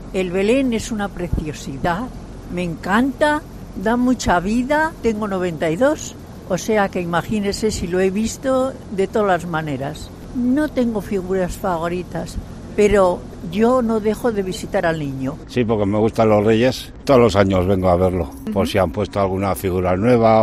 Visitantes del Belén de La Florida